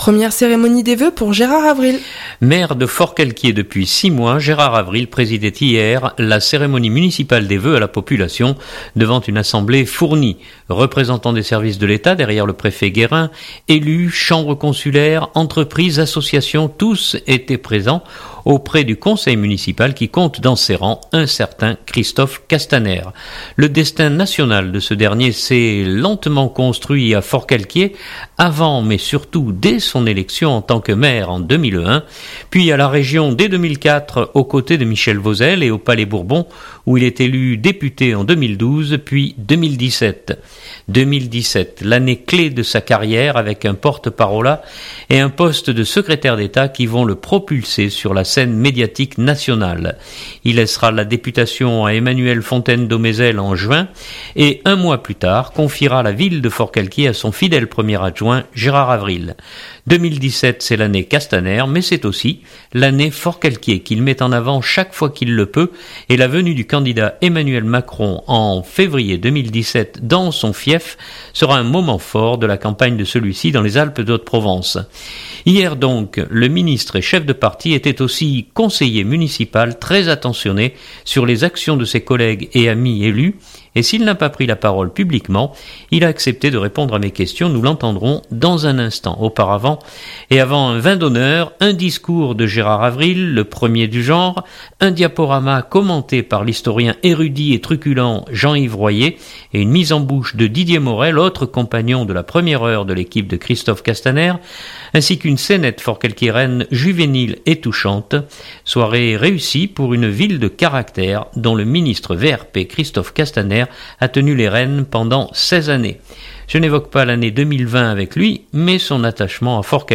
Hier donc le ministre et chef de parti était aussi conseiller municipal très attentionné sur les actions de ses collègues et amis élus et s’il n’a pas pris la parole publiquement, il a accepté de répondre à mes questions nous l’entendrons dans un instant.
Maire de Forcalquier depuis 6 mois, Gérard Avril présidait hier la cérémonie municipale des vœux à la population devant une assemblée fournie.